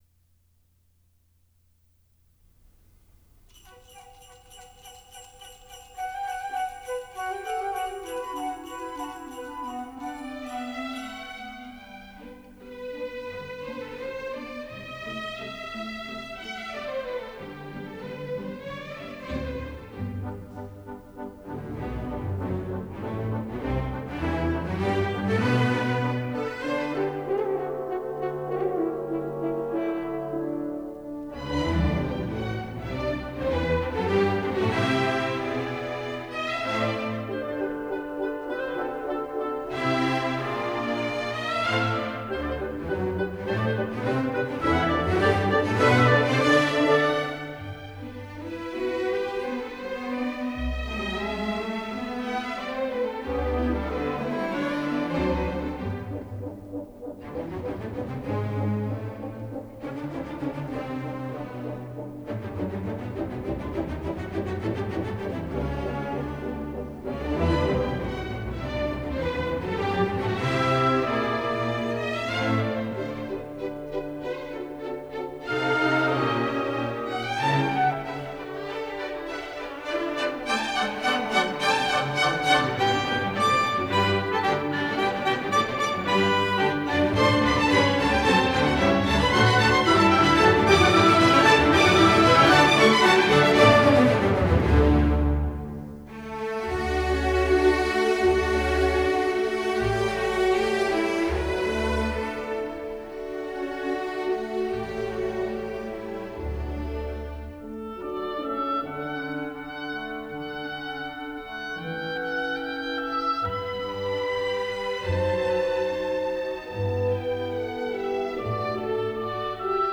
Soprano
Venue: Orchestra Hall, Chicago